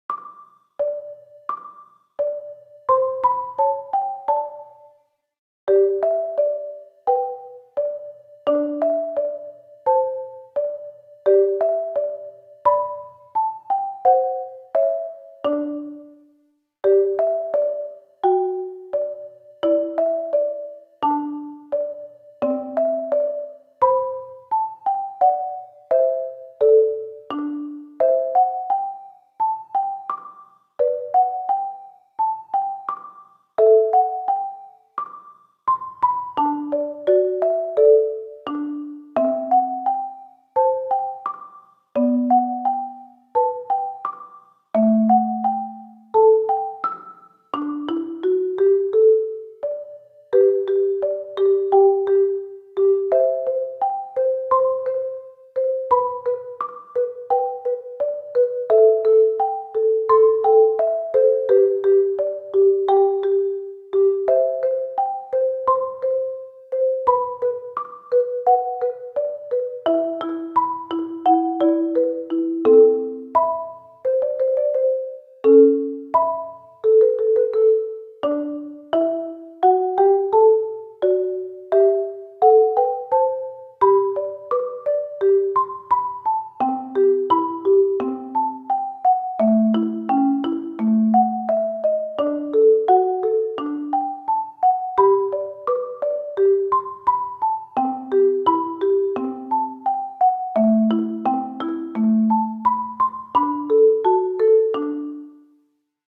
ogg(L) 癒し マリンバ ゆったり
マリンバ２和音だけの癒しＢＧＭ。